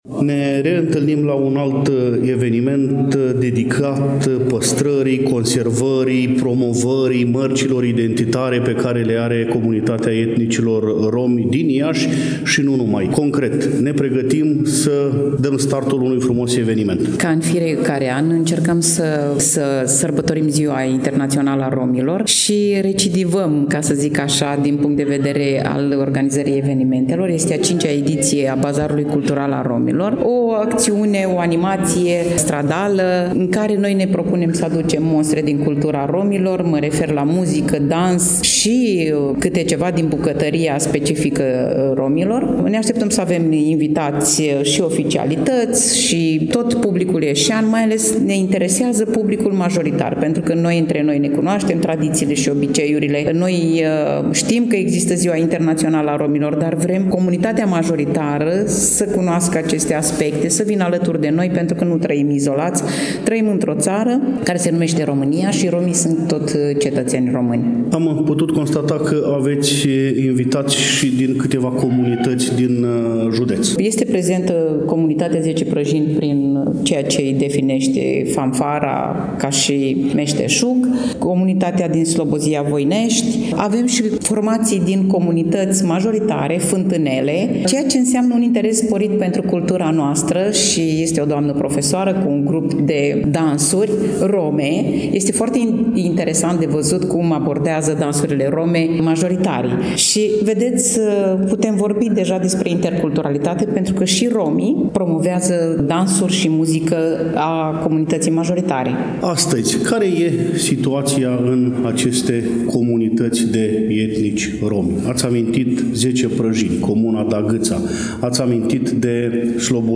Evenimentul s-a desfășurat în Parcul Copou din târgul Iașilor.